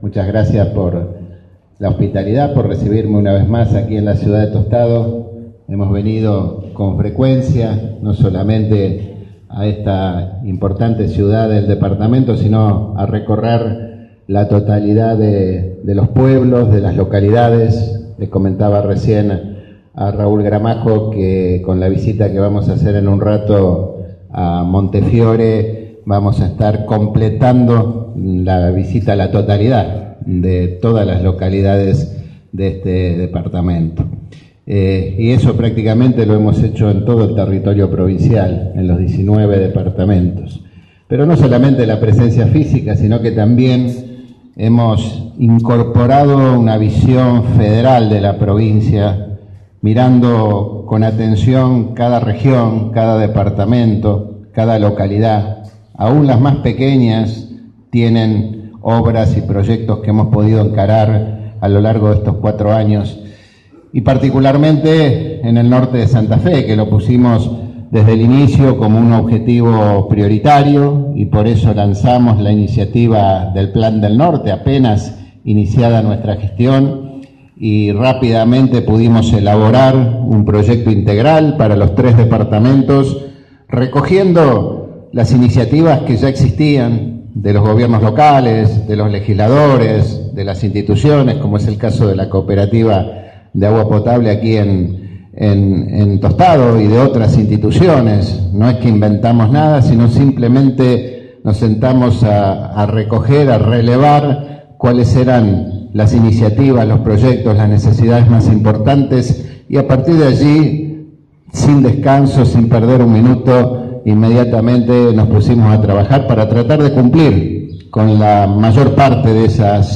Del acto, que se realizó en la sede de la cooperativa de Provisión de Agua Potable, Vivienda y Servicios Asistenciales de Tostado Limitada, participaron también el ministro de Infraestructura y Transporte de la provincia, José Garibay; el secretario de Aguas y Saneamiento, Arnaldo Zapata; el secretario de coordinación y responsable del Plan del Norte, Sergio Rojas; y el presidente del Concejo Municipal de Tostado, Gerardo Bertolino, junto con integrantes del directorio de la Cooperativa y demás autoridades provinciales y locales.
Palabras del gobernador Miguel Lifschitz